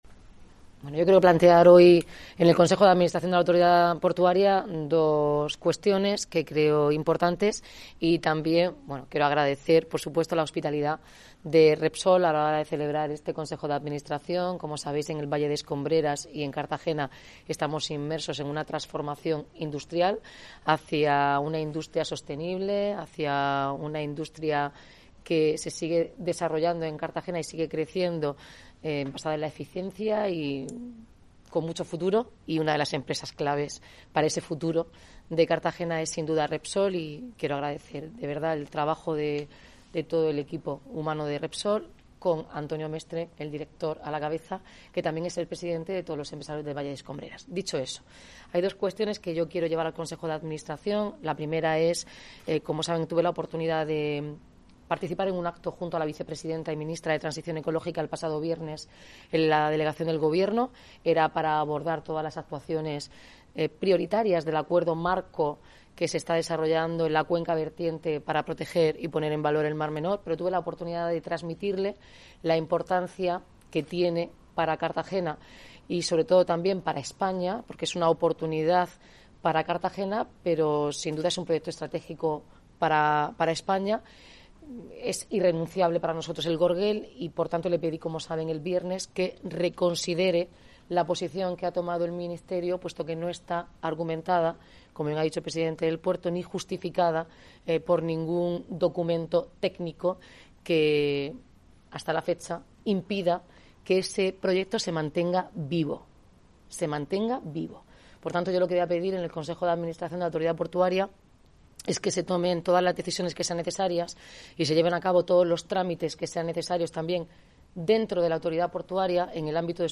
Audio: Declaraciones de Noelia Arroyo y Pedro Pablo Hernández.